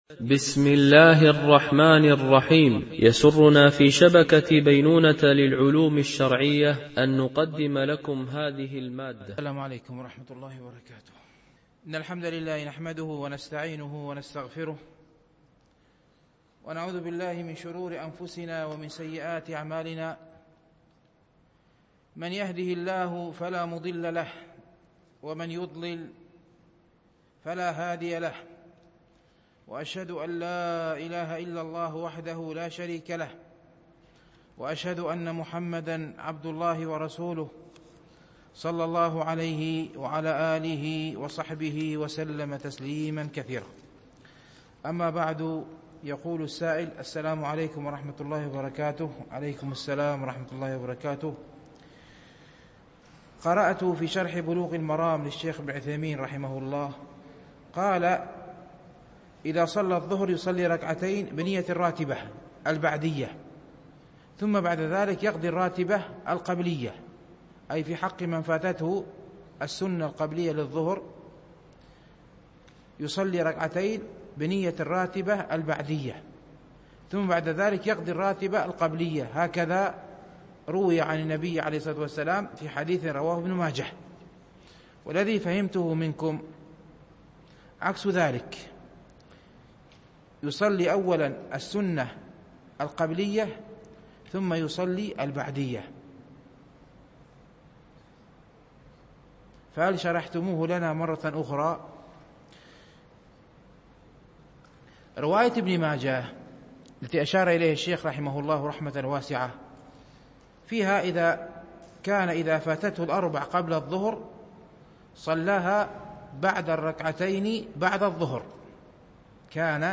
شرح رياض الصالحين – الدرس 292 ( الحديث 1139 - 1145 )